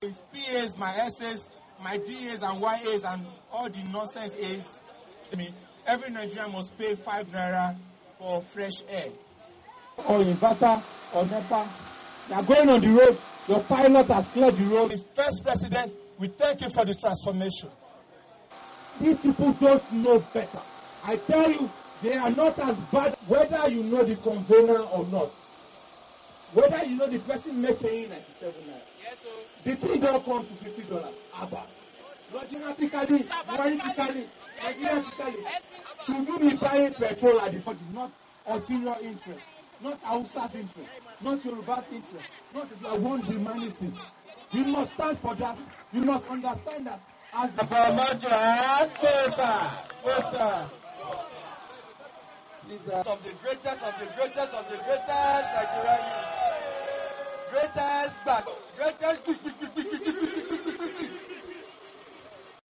VOA连线：朱立伦就任国民党主席，面对多项挑战